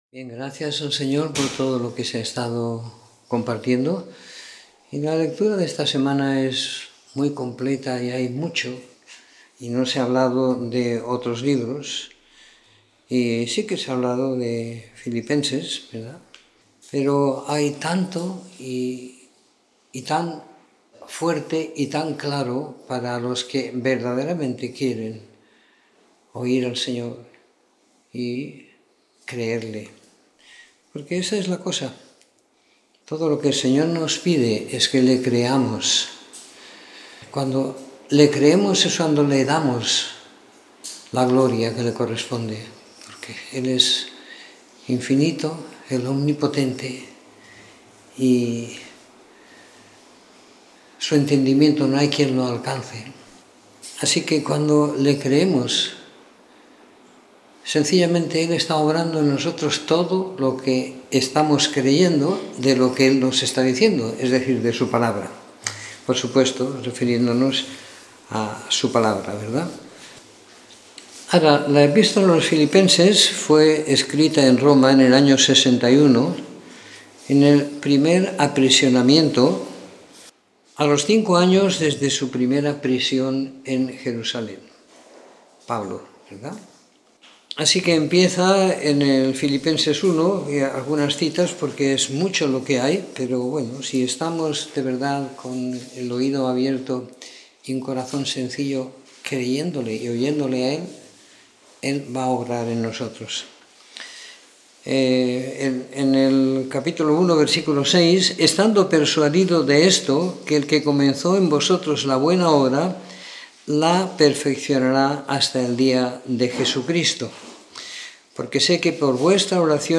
Comentario en la epístola a los Filipenses y a los Colosenses siguiendo la lectura programada para cada semana del año que tenemos en la congregación en Sant Pere de Ribes.